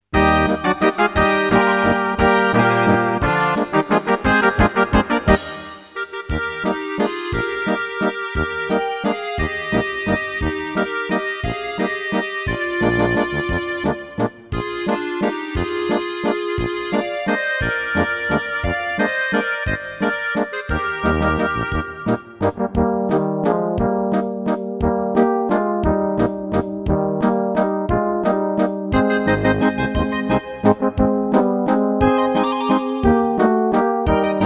Rubrika: Národní, lidové, dechovka
- valčík